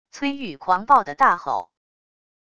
崔钰狂暴地大吼wav音频